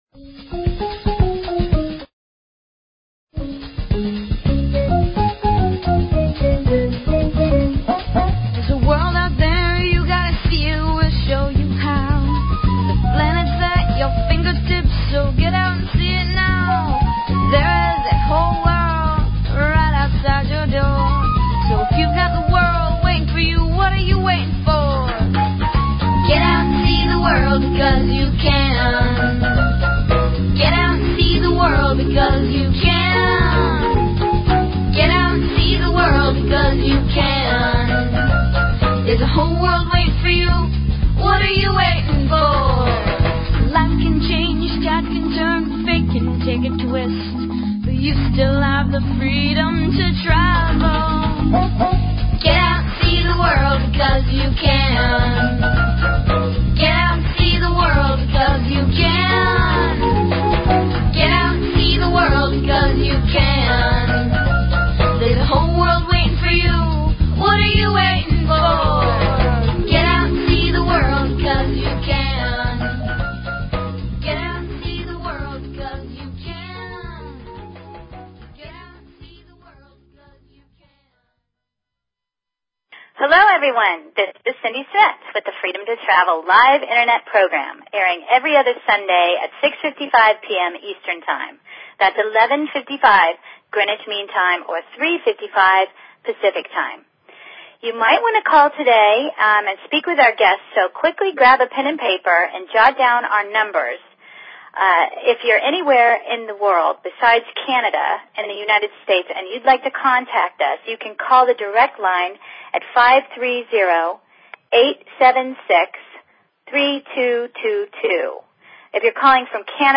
Talk Show Episode, Audio Podcast, Freedom_To_Travel and Courtesy of BBS Radio on , show guests , about , categorized as